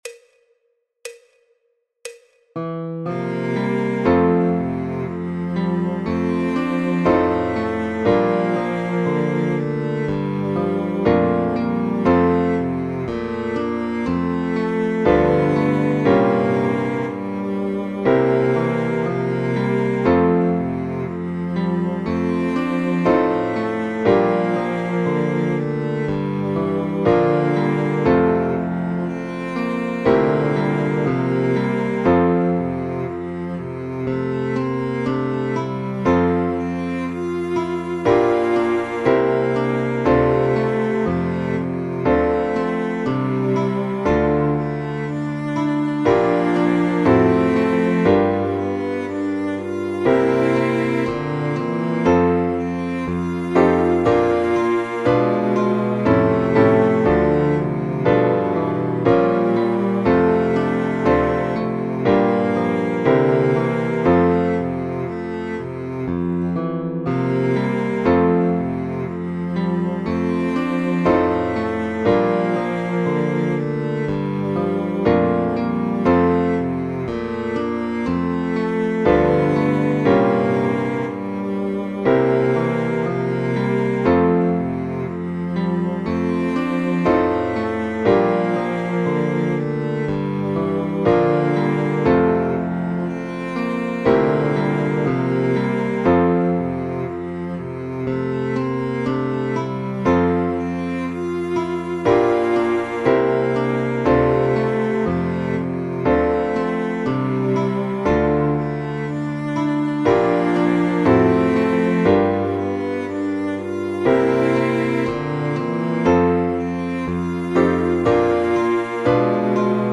El MIDI tiene la base instrumental de acompañamiento.